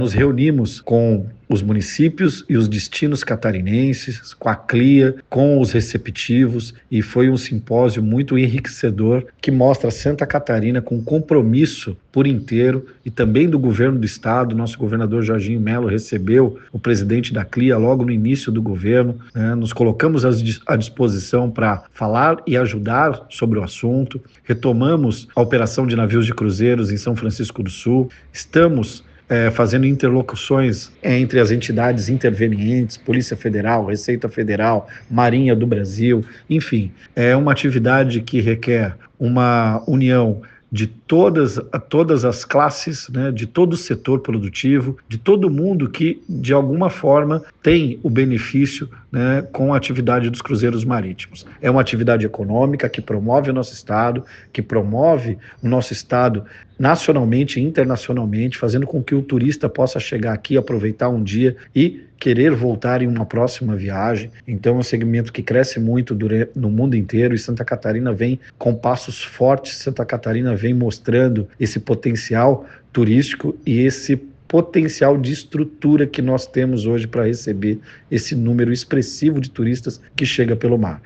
O secretário de Estado do Turismo participou do 2º Simpósio da Frente Parlamentar e destacou a importância de fortalecer o compromisso do estado com o turismo de cruzeiros:
SECOM-Sonora-Secretario-Turismo-Simposio-Navios-Cruzeiro.mp3